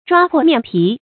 抓破面皮 注音： ㄓㄨㄚ ㄆㄛˋ ㄇㄧㄢˋ ㄆㄧˊ 讀音讀法： 意思解釋： 見「抓破臉皮」。